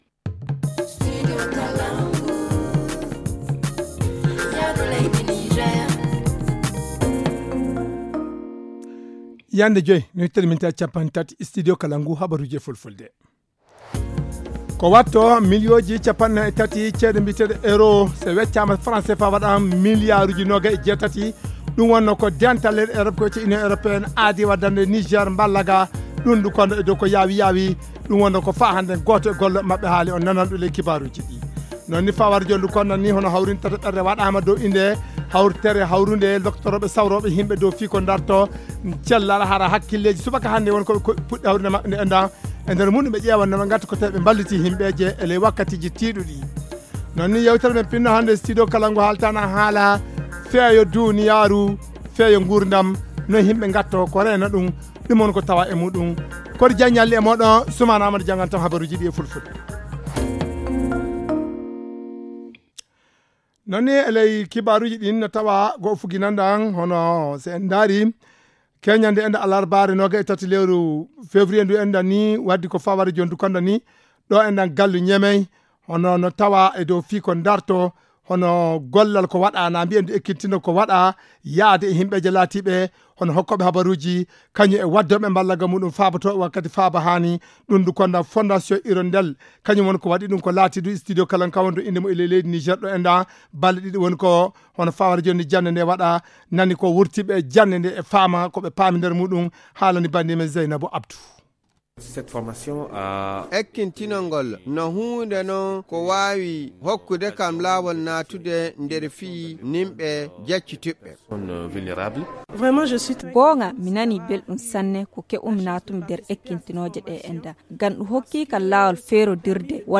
Le journal du 24 février 2022 - Studio Kalangou - Au rythme du Niger